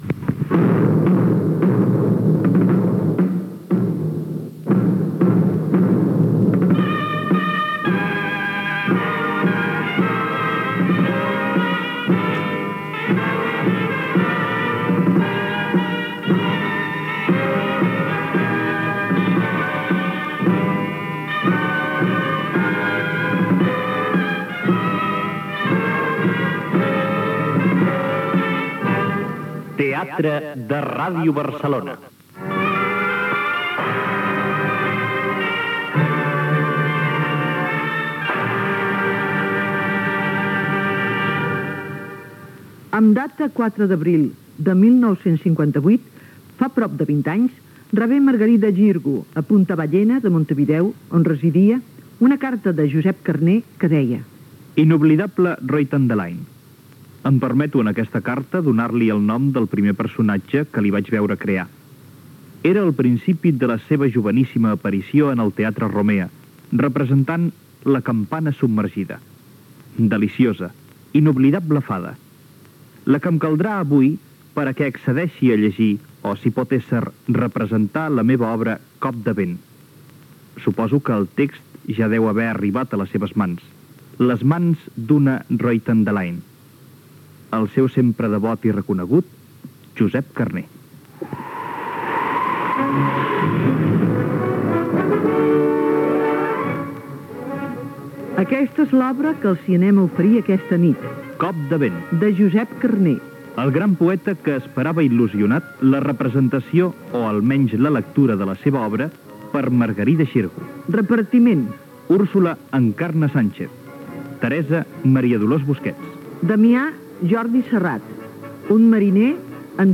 Versió radiofònica de l'obra de Josep Carner «Cop de vent». Careta, lectura de la carta de l'escriptor Josep Carner adreçada a l'actriu Margarida Xirgu, repartiment. Diàleg entre Úrsula i Teresa.